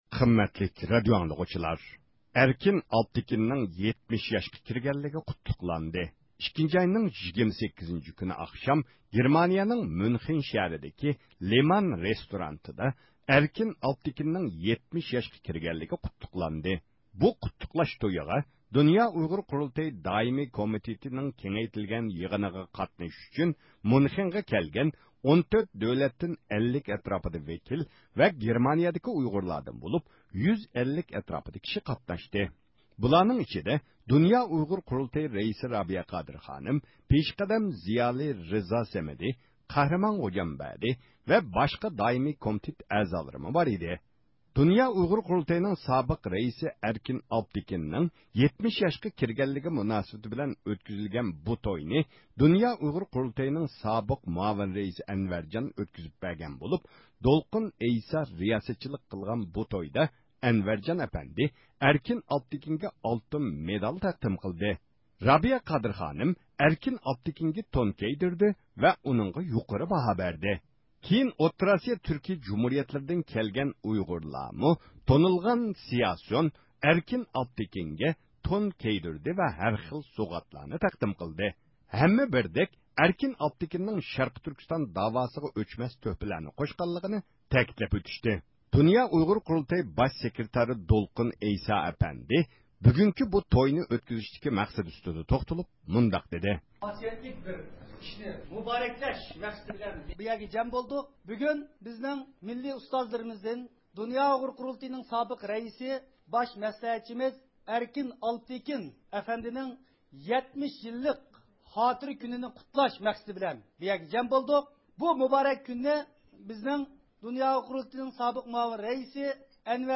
ﻣﯘﺧﺒﯩﺮﯨﻤﯩﺰ